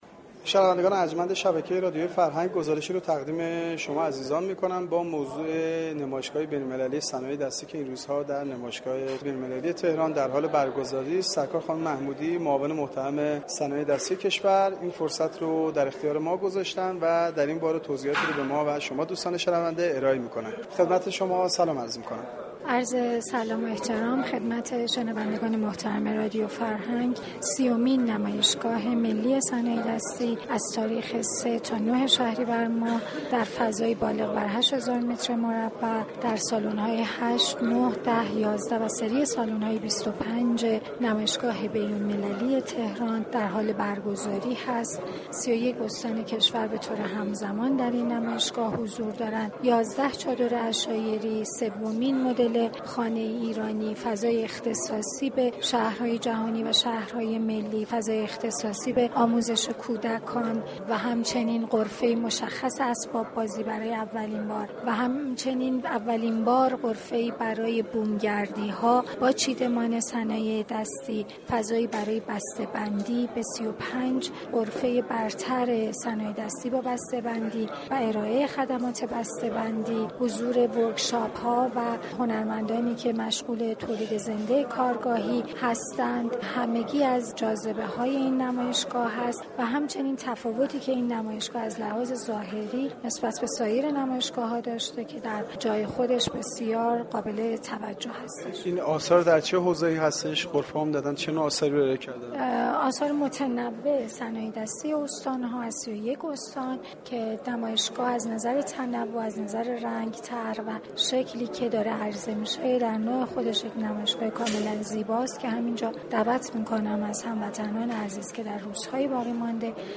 دكتر پویا محمودیان معاون صنایع دستی كشور در گفتگو با گزارشگر رادیو فرهنگ